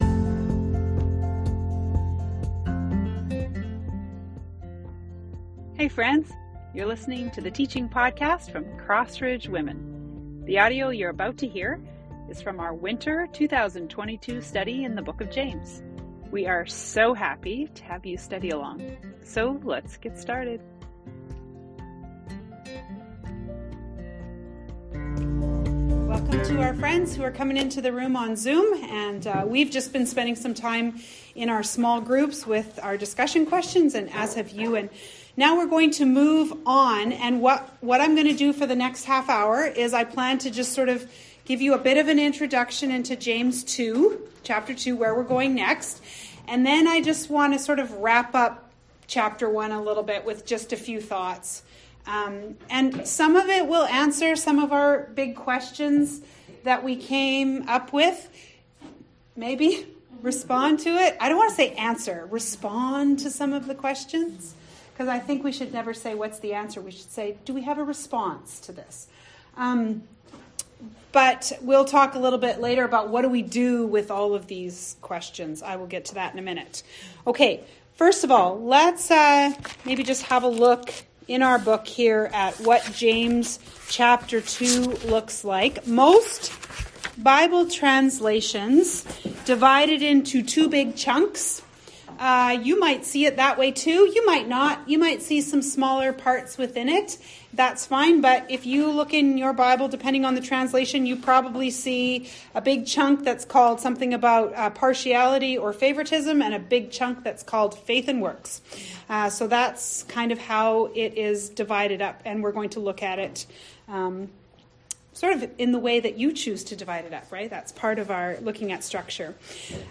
A wrap up discussion from our time in James 1 and a look ahead at what we'll encounter in Chapter 2.